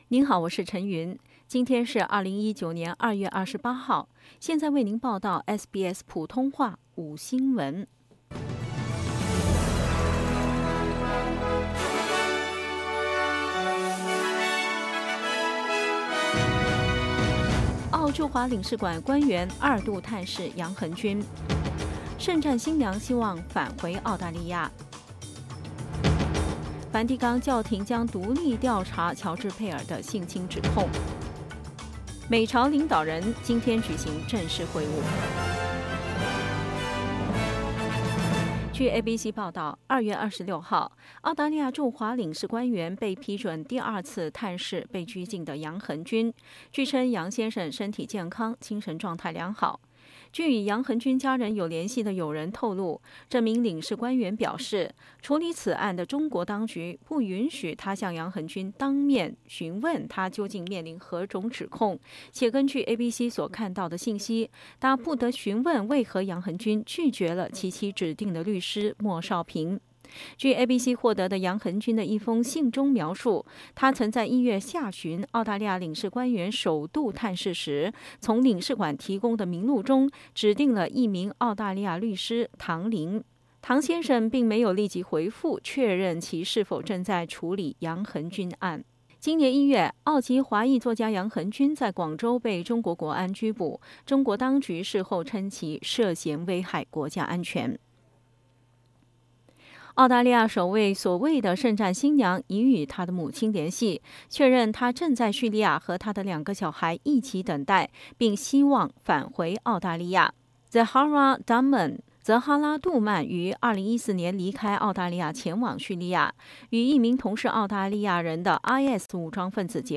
SBS午新闻（2月28日）